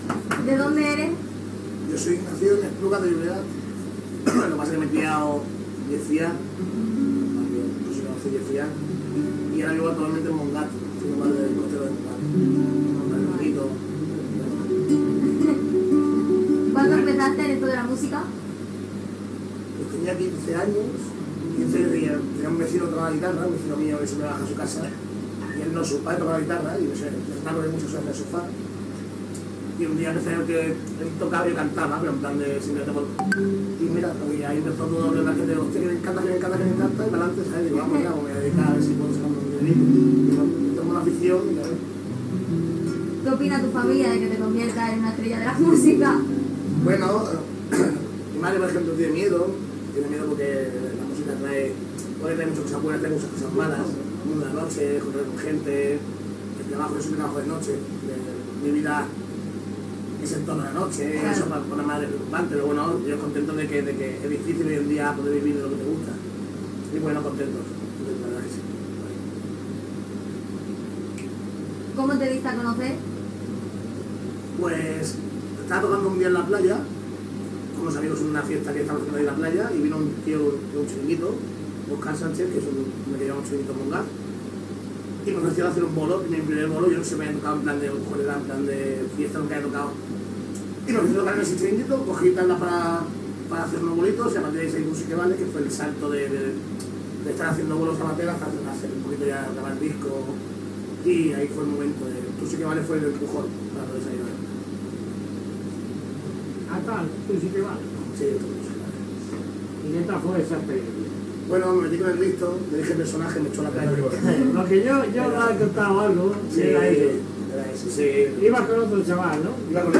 Primera entrevista feta a Radio Pueblo Nuevo
FM